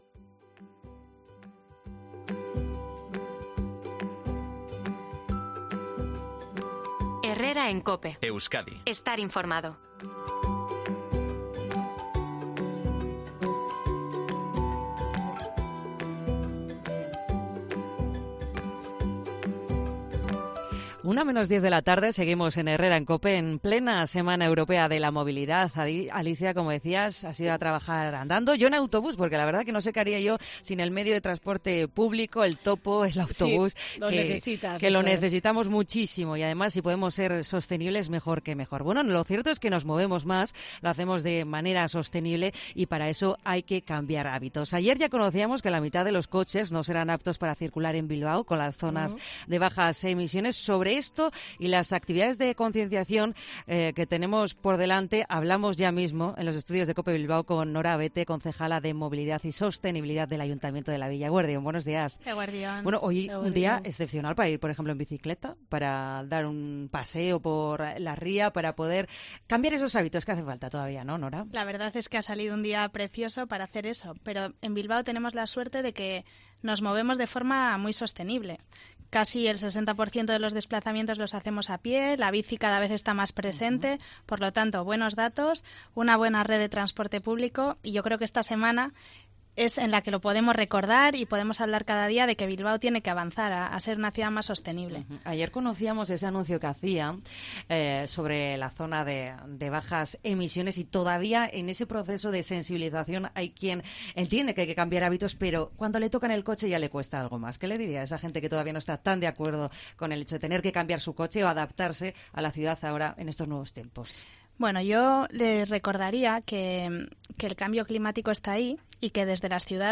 En una entrevista en COPE Euskadi, la Teniente de Alcalde y Concejala de Movilidad y Sostenibilidad, Nora Abete, insiste en su propósito de "mejorar la calidad de aire y mejorar la salud" de los bilbaínos con "medias progresivas" a las que habrá que irse adaptando.